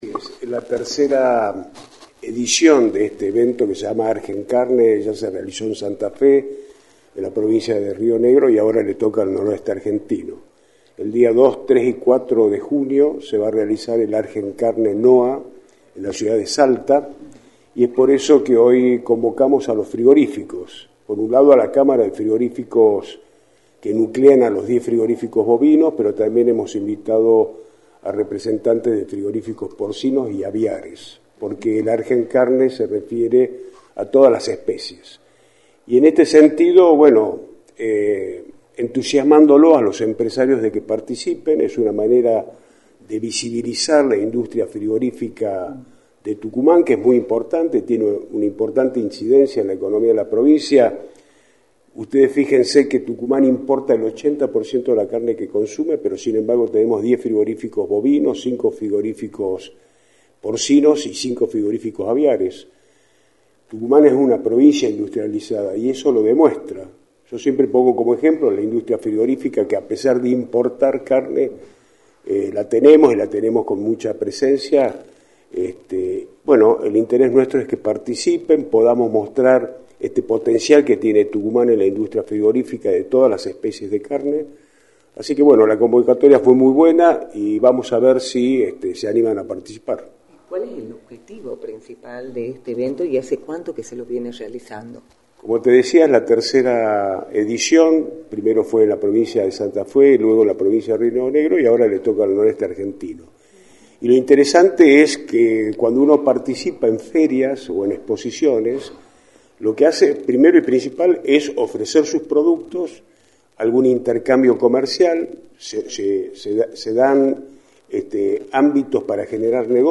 “El interés nuestro es que participen y que puedan demostrar ese potencial que tenemos en todas las especies de carnes, la convocatoria fue buena y esperemos que puedan participar del evento” señaló Álvaro Simón Padrós, Ministro de Desarrollo Productivo, en entrevista con Radio del Plata, por la 93.9.